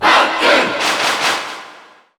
Category: Piranha Plant (SSBU) Category: Crowd cheers (SSBU) You cannot overwrite this file.
Piranha_Plant_Cheer_Japanese_SSBU.ogg